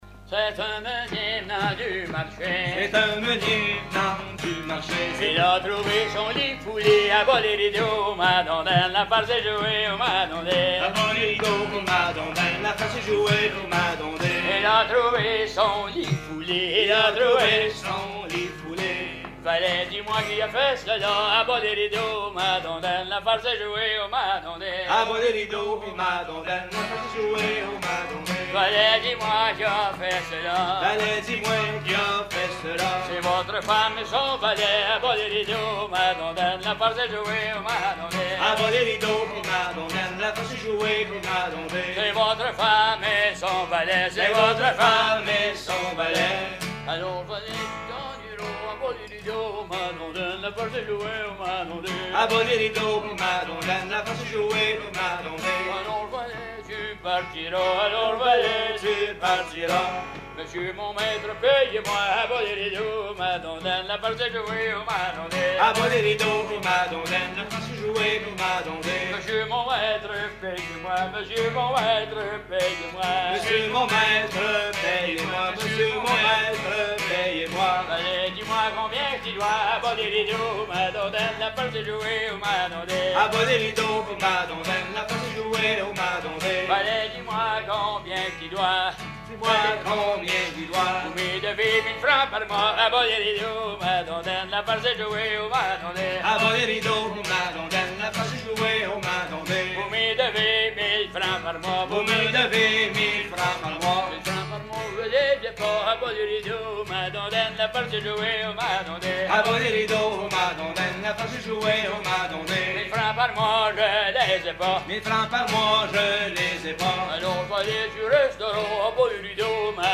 Mémoires et Patrimoines vivants - RaddO est une base de données d'archives iconographiques et sonores.
Veillée québécoise à la Ferme du Vasais
Pièce musicale inédite